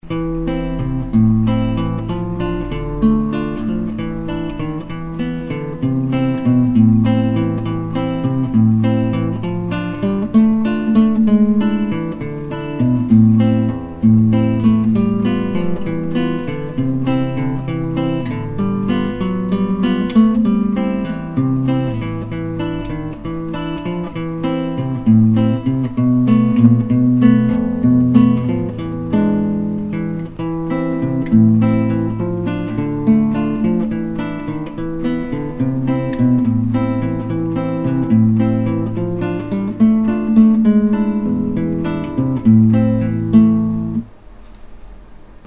[Emilio Pujol] Etude - Guitare Classique
Le chant de la basse y est vraiment.
Si je peux me permettre, est-ce que tu n'accélères pas vers la fin ?
que tu acceleres sur la fin.